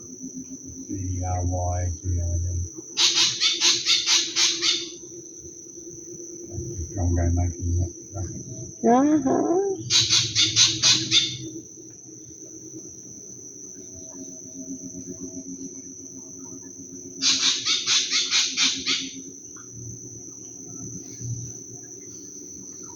Drongo Escamoso (Dicrurus bracteatus)
Localidad o área protegida: Sydney
Localización detallada: Royal Botanic Gardens
Condición: Silvestre
Certeza: Vocalización Grabada
spangled-drongo.mp3